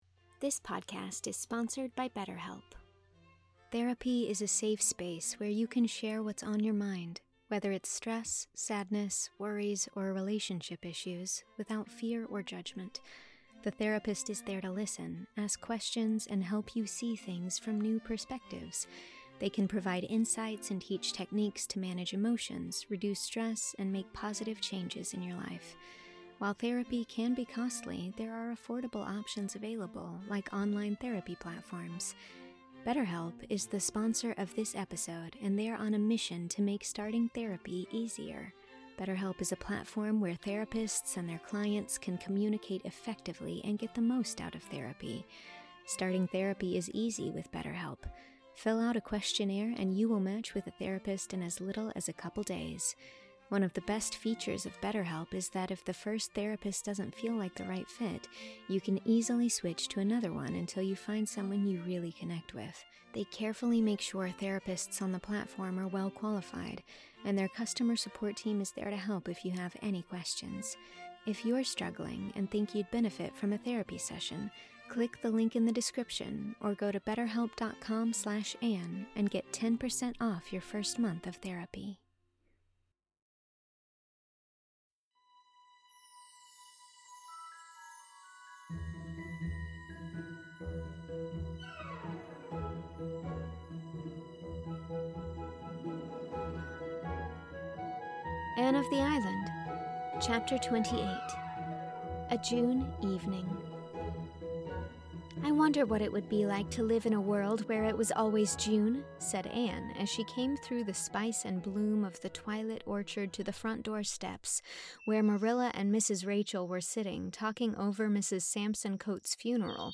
Listen to The Case of the Greater Gatsby to hear more fiction audio content produced by me.